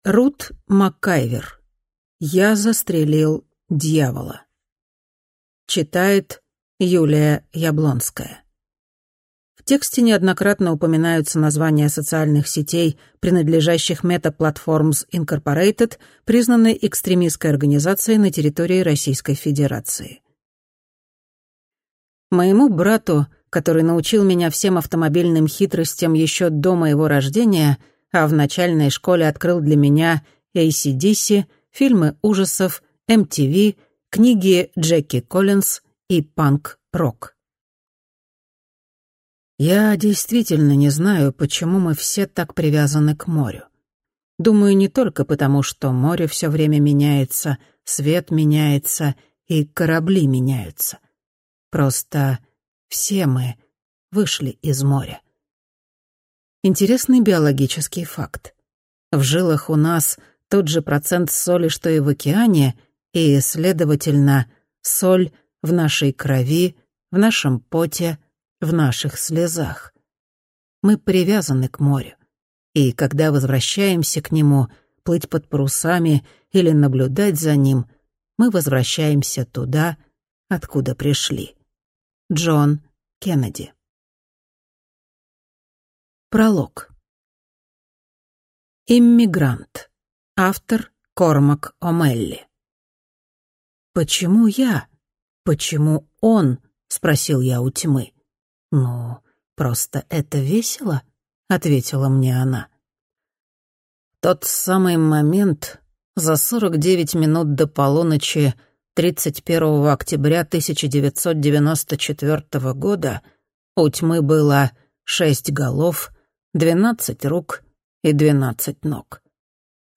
Аудиокнига Я застрелил дьявола | Библиотека аудиокниг